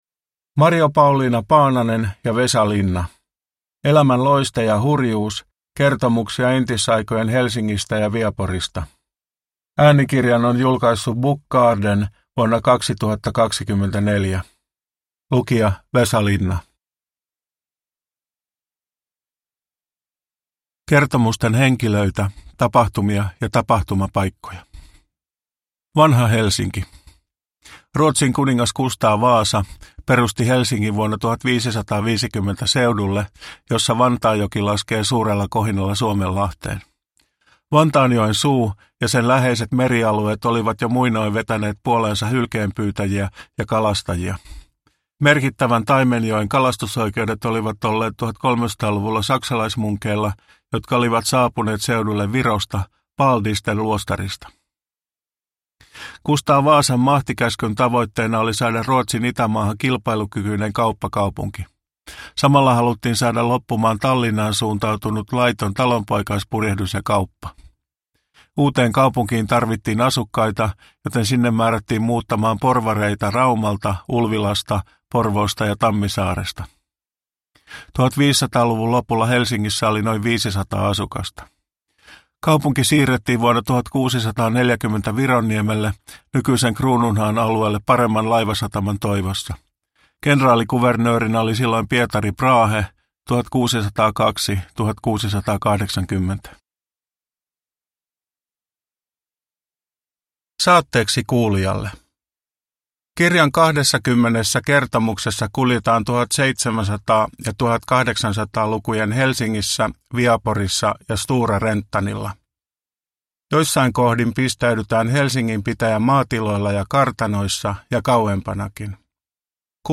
Elämän loiste ja hurjuus – Ljudbok